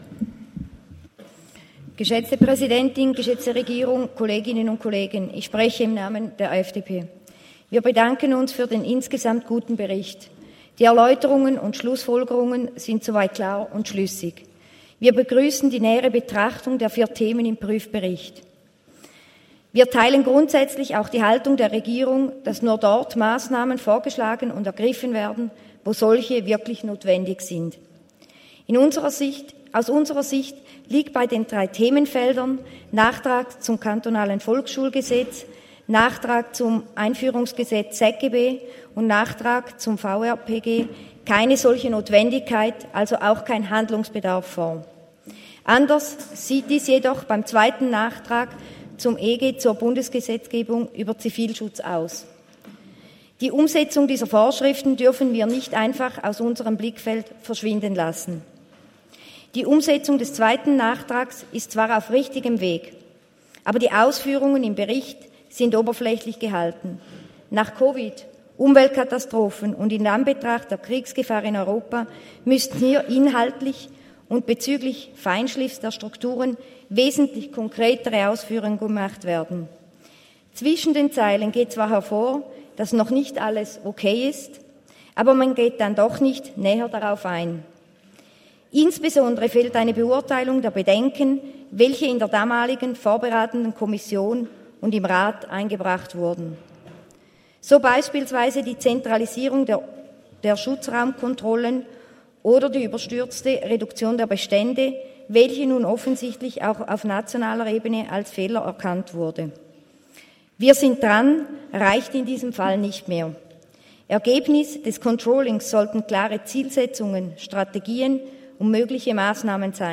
Session des Kantonsrates vom 18. bis 20. September 2023, Herbstsession
19.9.2023Wortmeldung